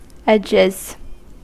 Ääntäminen
Ääntäminen US Tuntematon aksentti: IPA : /ˈɛdʒɪz/ Haettu sana löytyi näillä lähdekielillä: englanti Käännöksiä ei löytynyt valitulle kohdekielelle. Edges on sanan edge monikko.